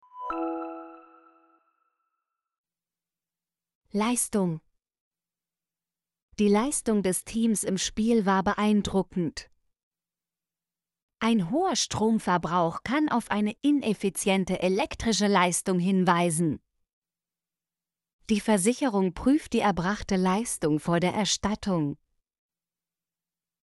leistung - Example Sentences & Pronunciation, German Frequency List